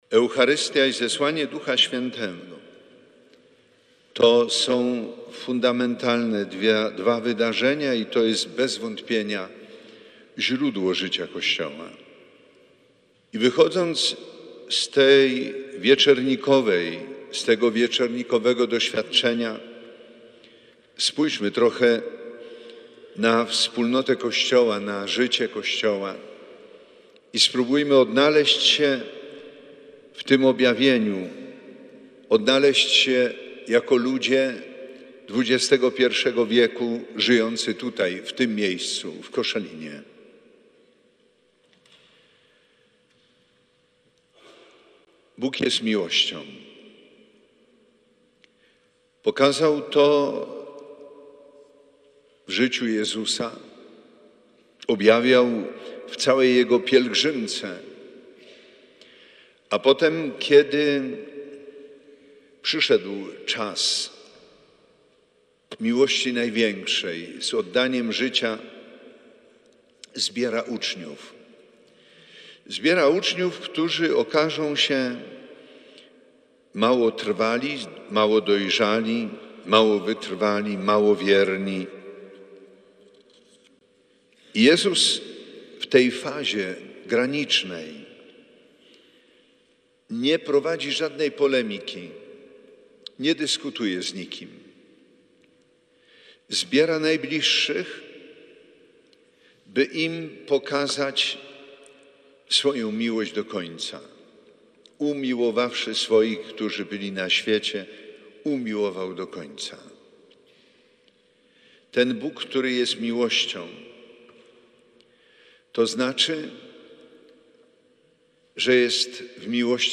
Bp Edward Dajczak przewodniczył Mszy św. w wigilię Zesłania Ducha Świętego podczas modlitewnego czuwania w Koszalinie.